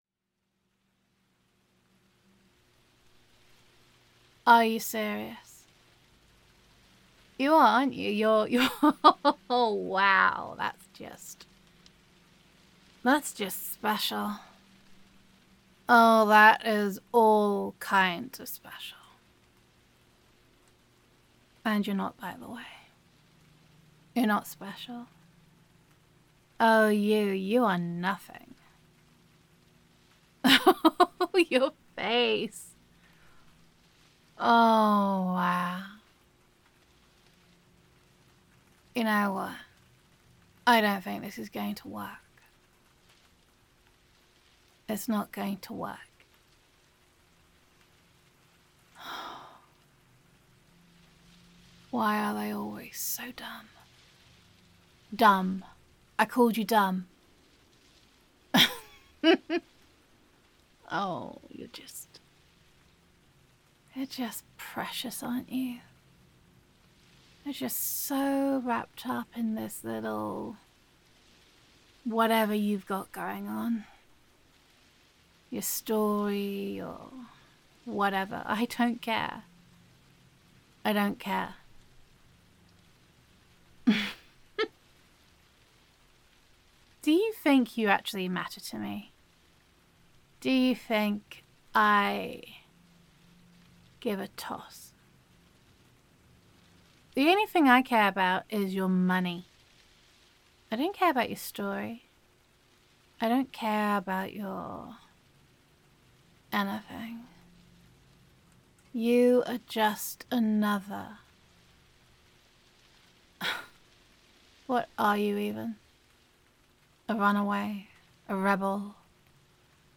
[F4A]